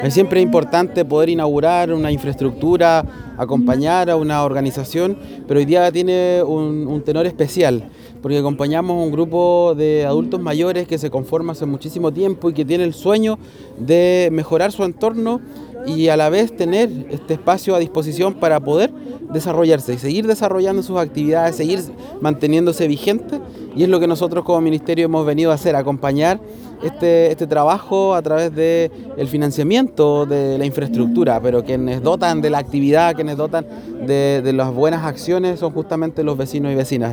El Seremi de Vivienda y Urbanismo, destacó el trabajo que se desarrolla por el bienestar de las personas mayores, quienes durante mucho tiempo lucharon en la búsqueda de un espacio comunitario.